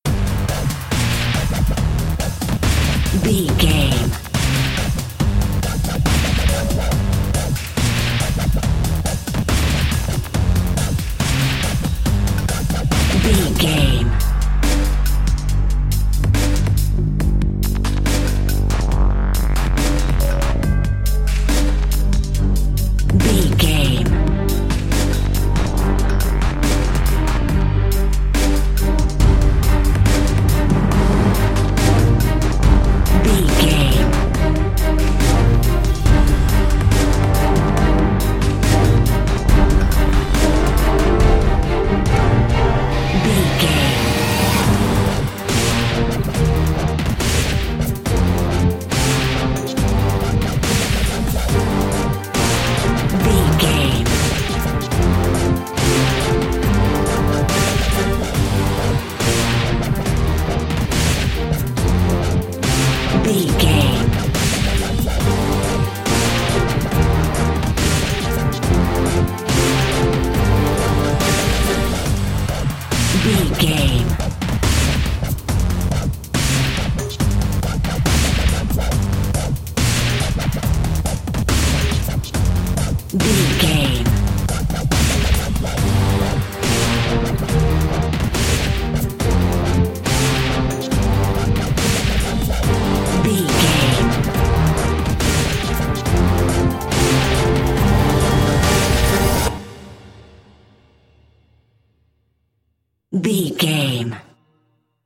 Aeolian/Minor
strings
drums
drum machine
synthesiser
brass
orchestral
orchestral hybrid
dubstep
aggressive
energetic
intense
synth effects
wobbles
driving drum beat
epic